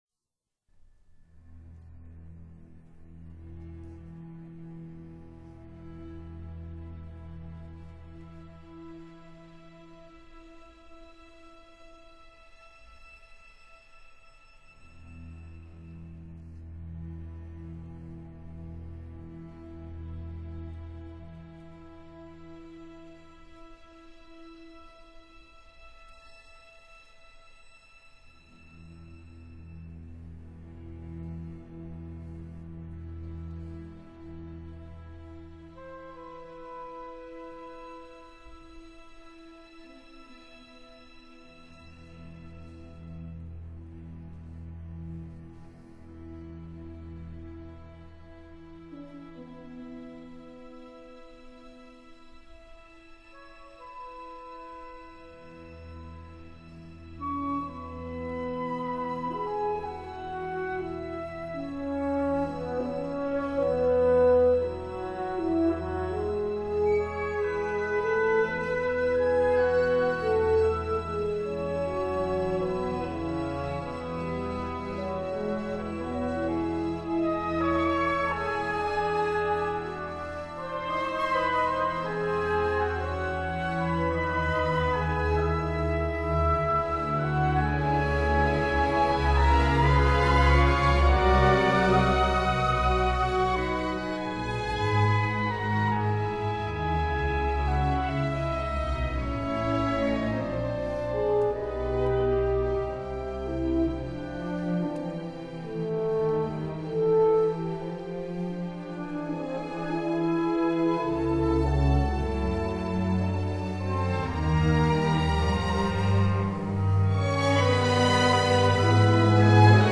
symphonic variations